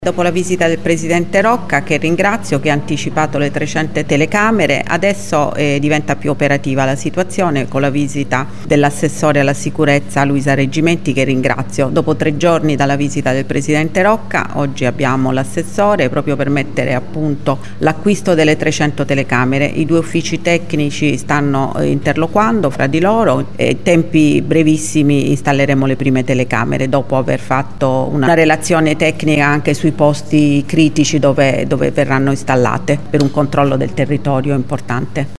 Il piano “telecamere” passa alla sua fase operativa ha sottolineato il sindaco Celentano: